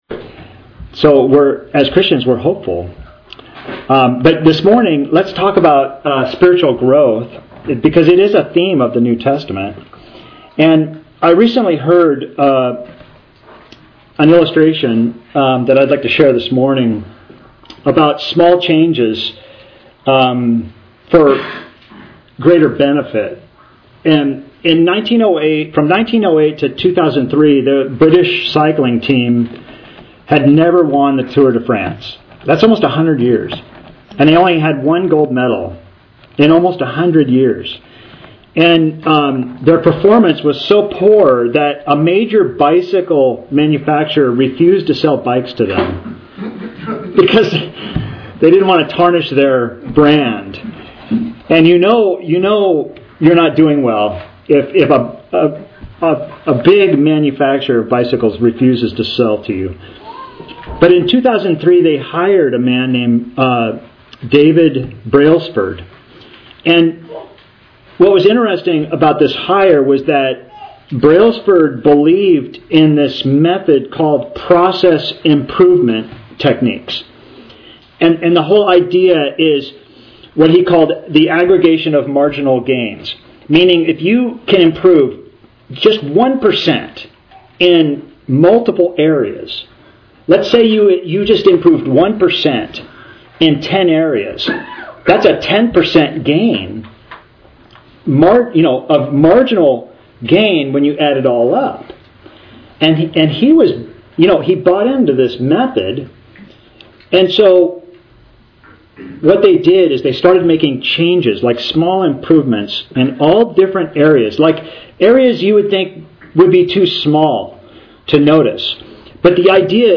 Author jstchurchofchrist Posted on March 19, 2026 Categories Sermons